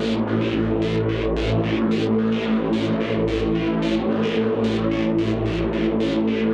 Index of /musicradar/dystopian-drone-samples/Tempo Loops/110bpm
DD_TempoDroneC_110-A.wav